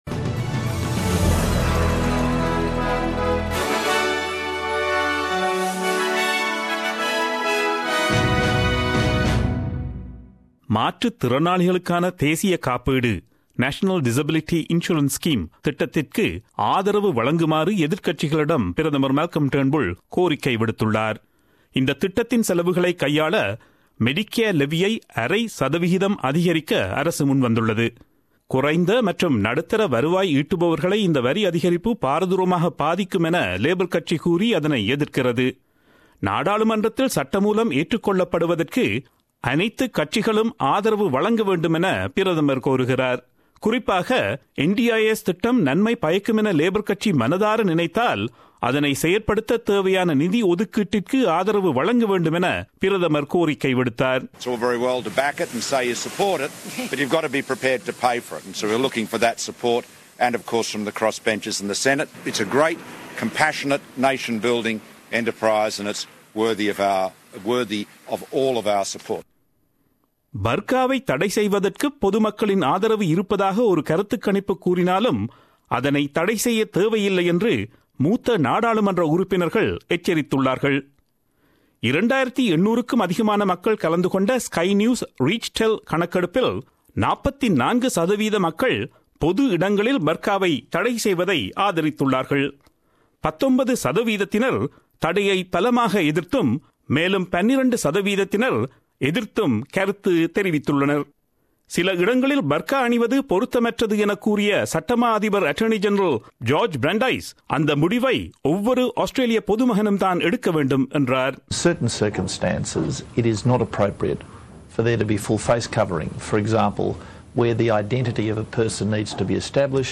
Australian news bulletin aired on Friday 25 August 2017 at 8pm.